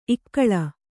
♪ ikkaḷa